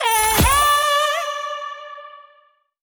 wild_2_win.wav